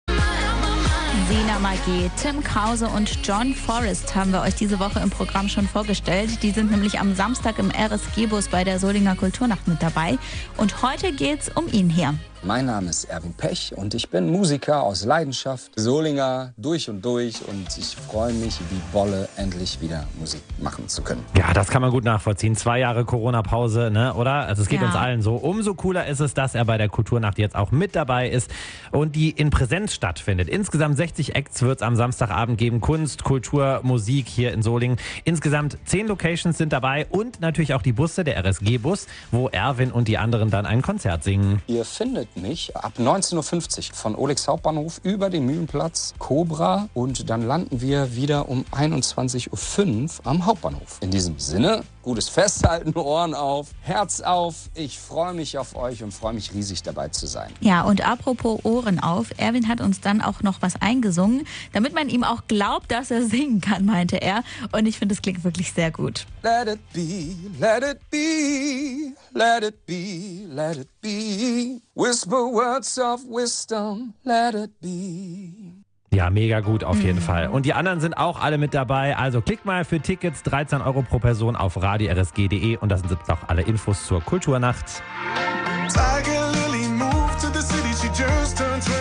Soul, Blues, Rock und Pop
Im Gepäck: immer seine Gitarre und ein offenes Ohr für Spontaneität. https
Kultur Nacht Solingen 2022